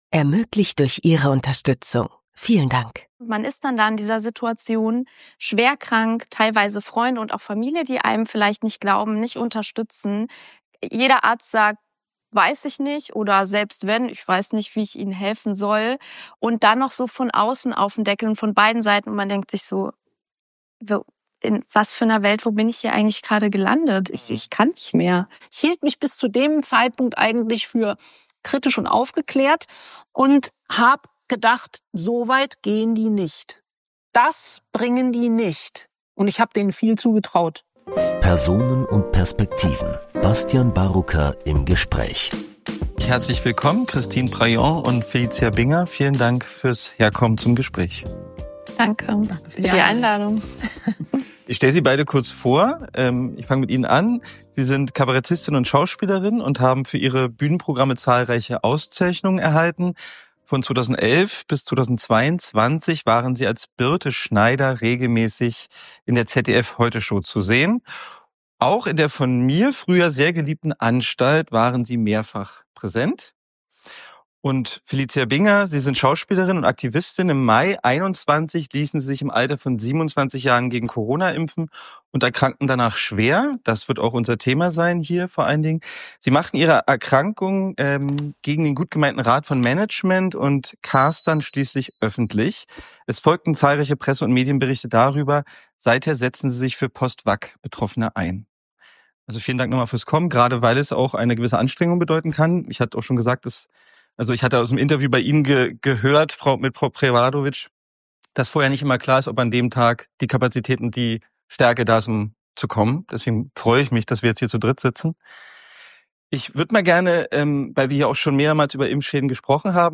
Im Gespräch erzählen sie von den Reaktionen des Publikums, dem starken Wunsch vieler Menschen, ihr Schicksal mitzuteilen, und der Schwierigkeit, ein Programm mit diesem brisanten Inhalt überhaupt auf eine Bühne in Deutschland zu bringen.